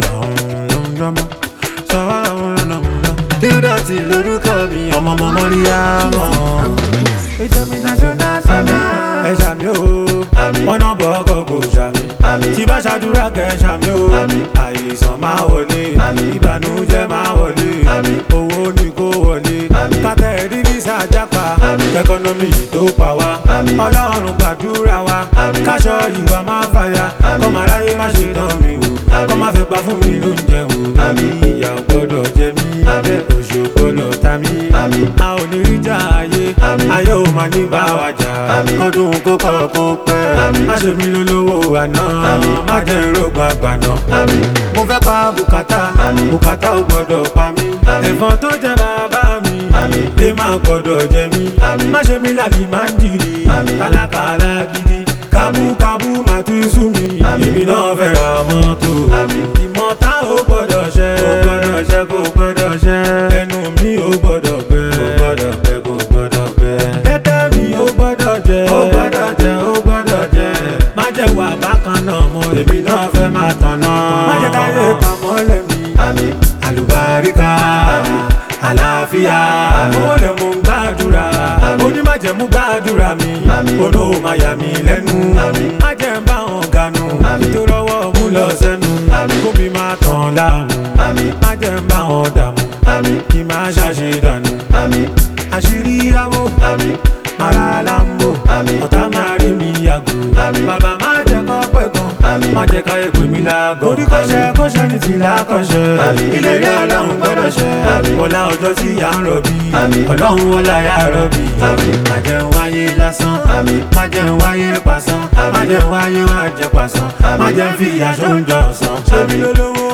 Soulful New Single
heartfelt and spiritually charged single
With its uplifting lyrics and easy manufacturing